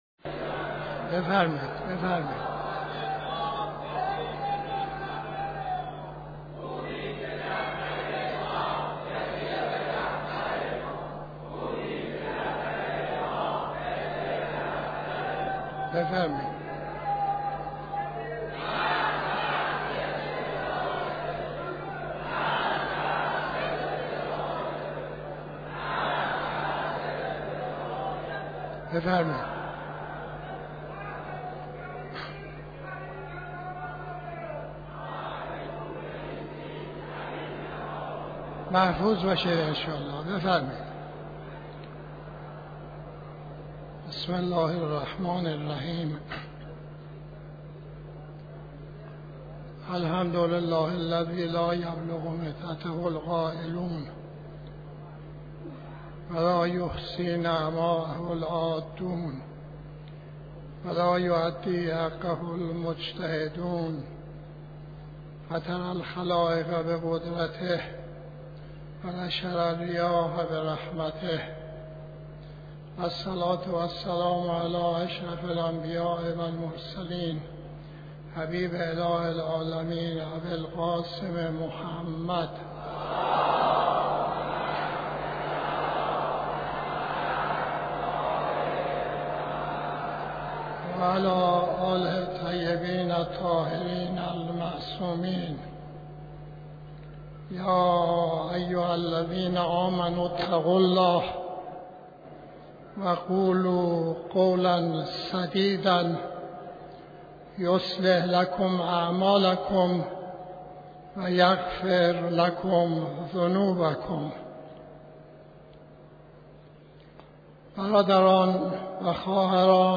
خطبه نماز جمعه 12-12-90